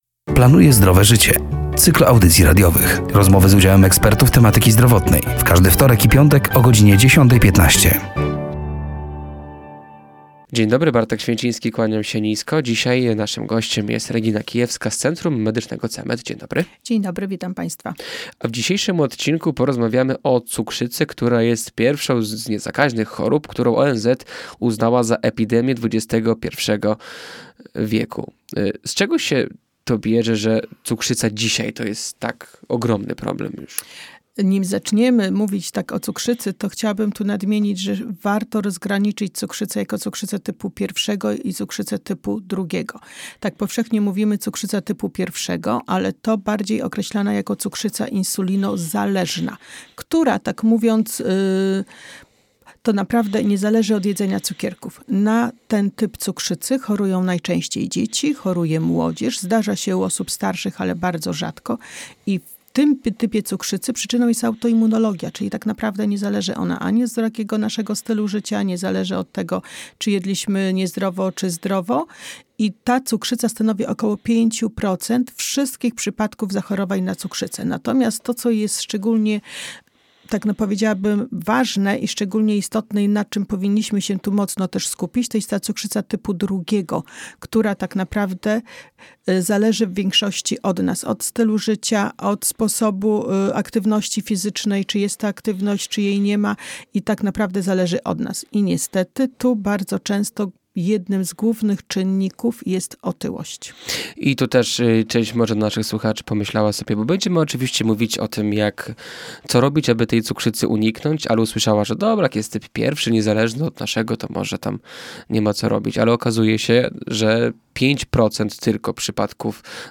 ,,Planuję Zdrowe Życie”, to cykl audycji radiowych poświęconych upowszechnianiu wiedzy z zakresu zdrowego stylu życia, promujących zdrowie i edukację zdrowotną. Rozmowy z udziałem ekspertów tematyki zdrowotnej.